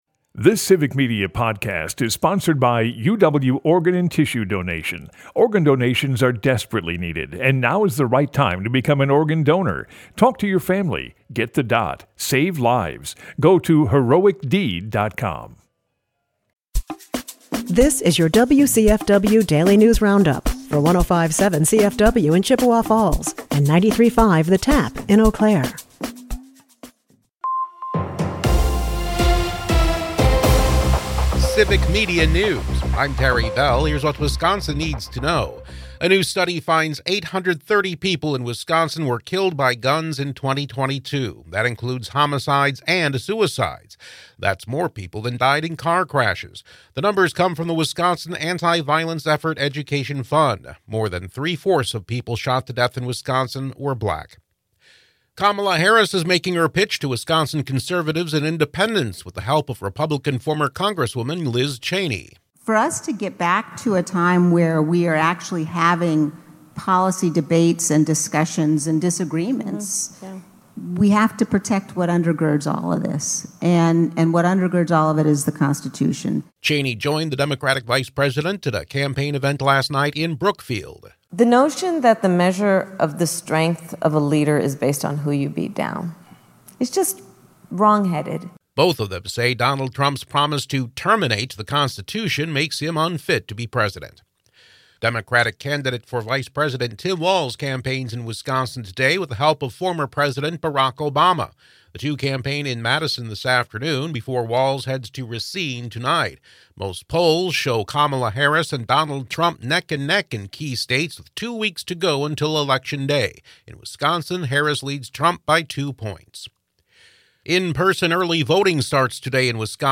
The WCFW Daily News Roundup has your state and local news, weather, and sports for Chippewa Falls, delivered as a podcast every weekday at 9 a.m. Stay on top of your local news and tune in to your community!